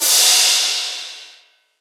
Crashes & Cymbals
DDW2 CRASH 2.wav